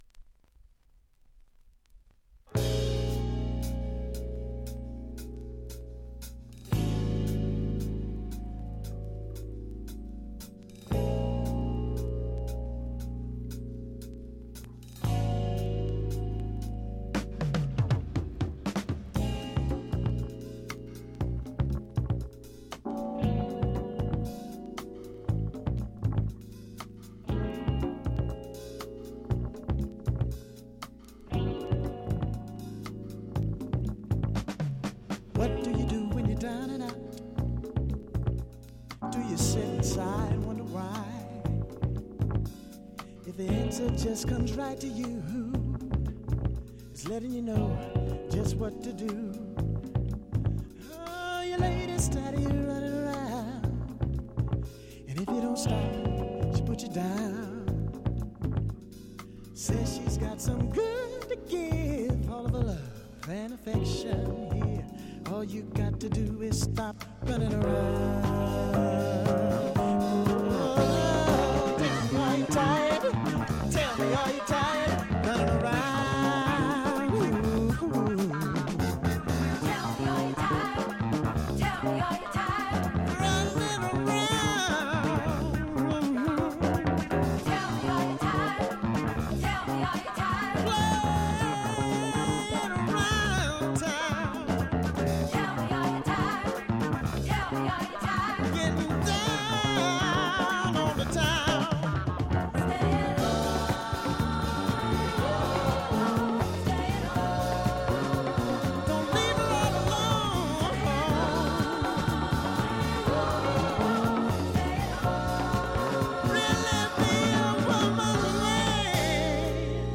Stereo LP